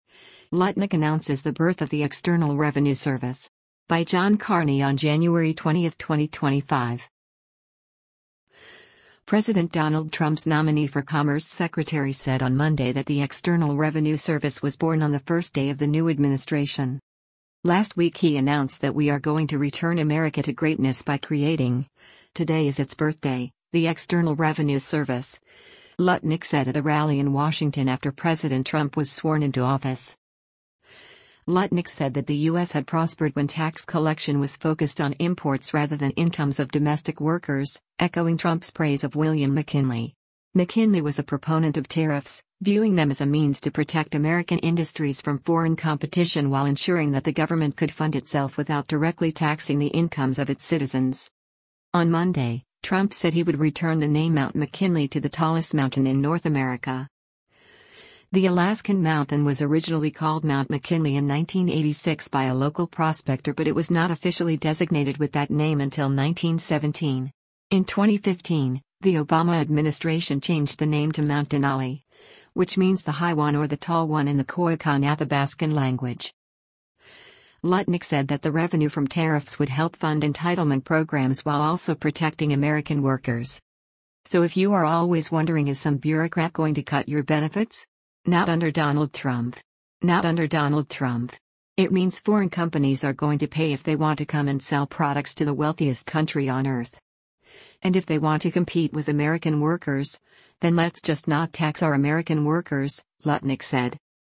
“Last week he announced that we are going to return America to greatness by creating—today is its birthday—the External Revenue Service,” Lutnick said at a rally in Washington after President Trump was sworn into office.